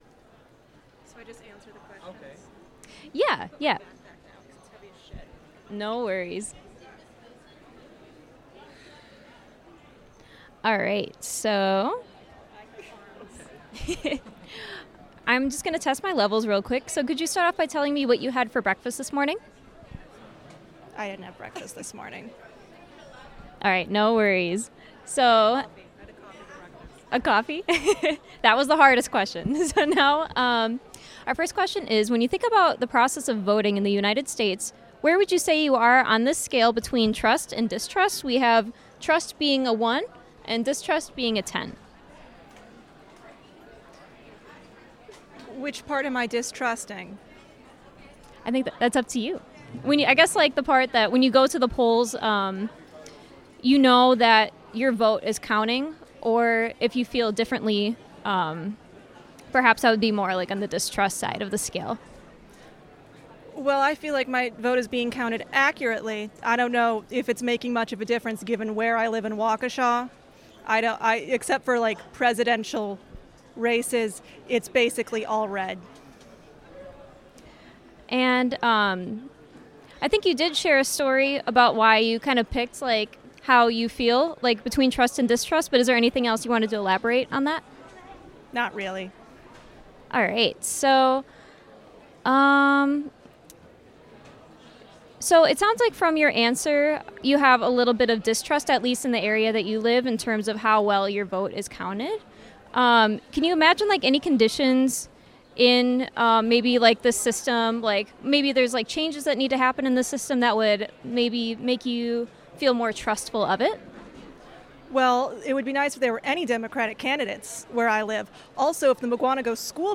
Participant II Interview
Location UWM Student Union